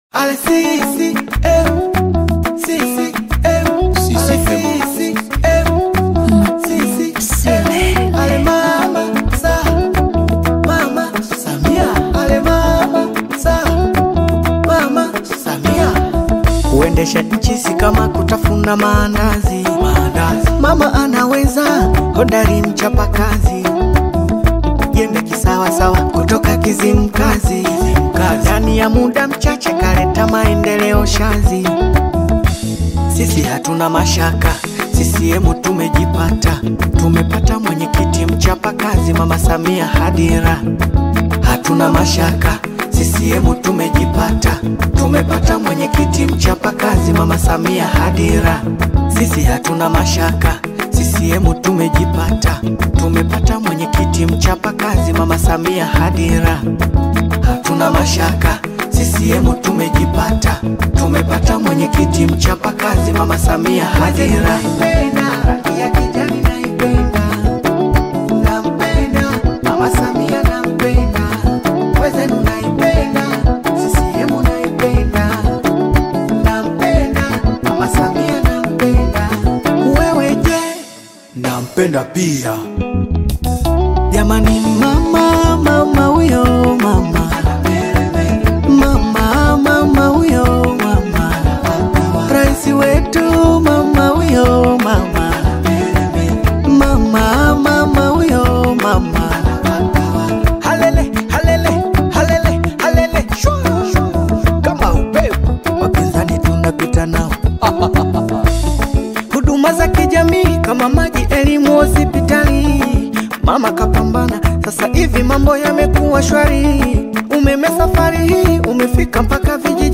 Audio, Nyimbo Za CCM, Nyimbo Za Chama CCM, Nyimbo Za Kampeni